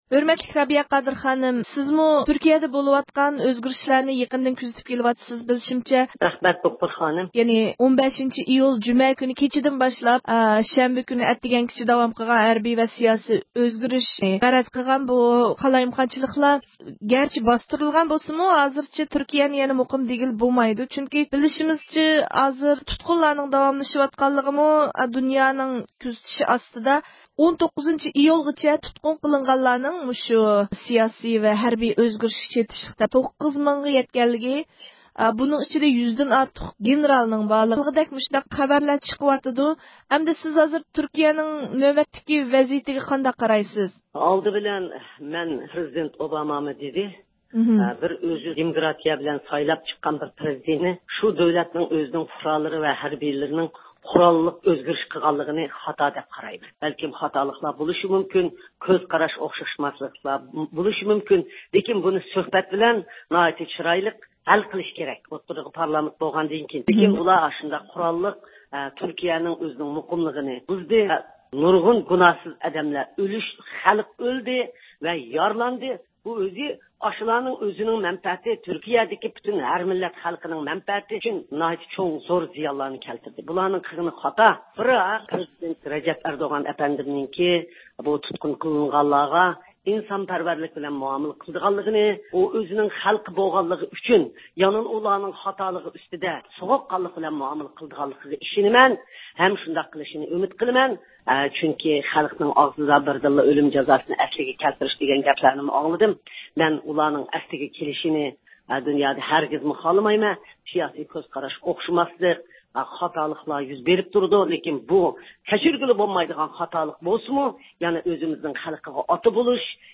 پۈتۈن دۇنيا تۈركىيەنىڭ «خائىنلارنى جازالاش» ھەرىكىتىنىڭ نەتىجىسىدىن ئەندىشىلىنىۋاتقان بىر پەيتتە، ئۆتكەن ھەپتە پارىژدا ئېچىلغان 5-نۆۋەتلىك دۇنيا ئۇيغۇر قۇرۇلتىيىدا قايتا رەئىسلىككە قايتا سايلانغان رابىيە قادىر خانىم بۈگۈن مەخسۇس رادىيو زىيارىتىمىزنى قوبۇل قىلىپ، تۈركىيە ۋەزىيىتىنى يېقىندىن كۆزىتىۋاتقانلىقىنى بىلدۈردى ۋە تۈركىيە ھۆكۈمىتىنى ھەمدە تۈركىيە پرېزىدېنتى رەجەپ تاييىپ ئەردوغاننى تىنچلىق ئۈچۈن سوغۇق قان بولۇشقا، جىنايەتچىلەرنى جازالاشتا خەلقنىڭ ئارزۇسى ۋە تۈركىيەنىڭ قىممەت قارشىغا ھۆرمەت قىلغاندىن سىرت يەنە، خەلقئارانىڭ تېگىشلىك قانۇنلىرىغا، كىشىلىك ھوقۇق مىزانلىرىغا ماس ھالدا تەدبىر كۆرۈشكە چاقىردى.